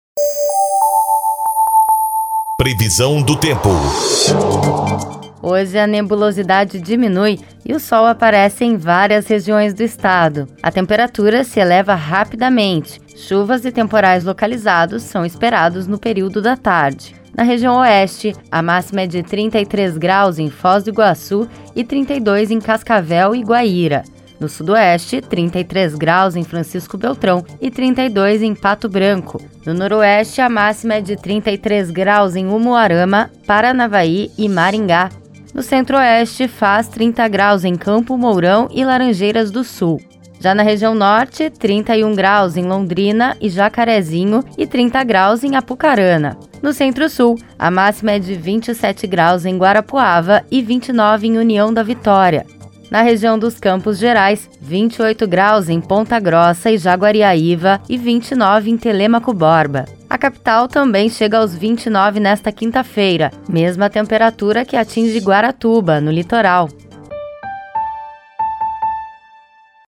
Previsão do tempo (20.01)